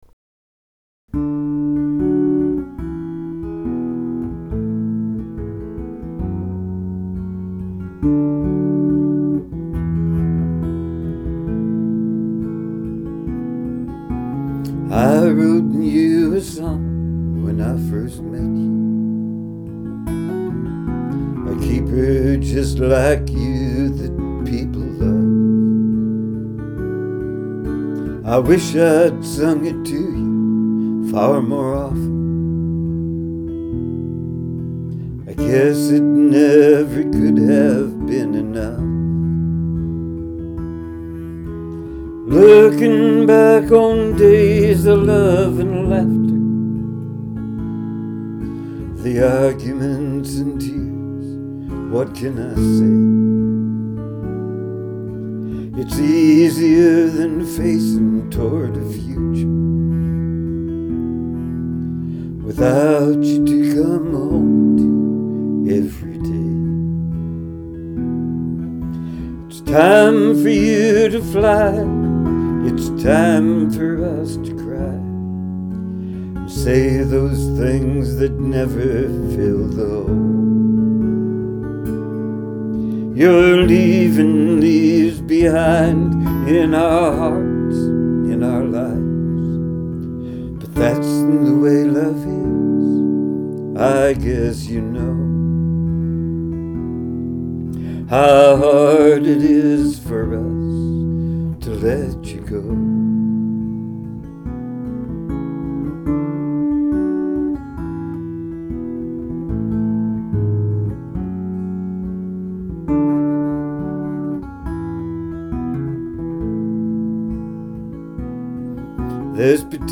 mostly just me and my guitars.